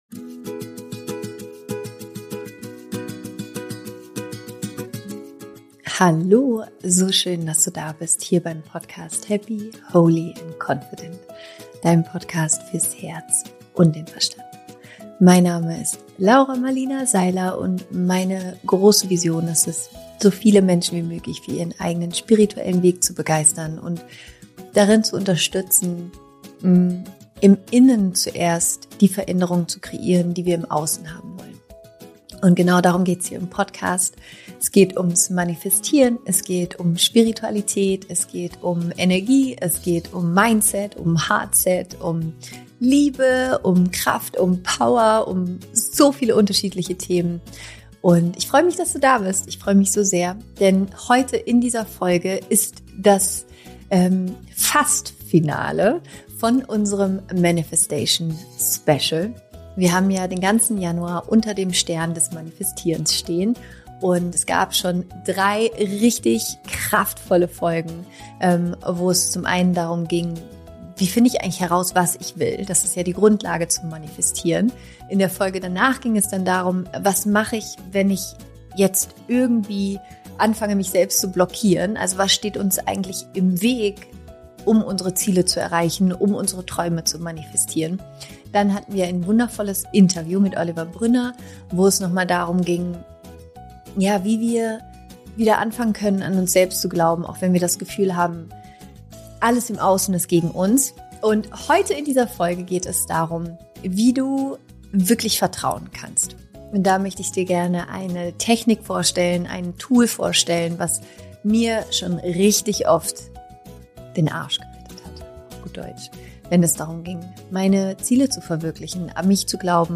Heute warten noch mal eine geballte Ladung Manifestationspower und eine wunderschöne Meditation auf dich!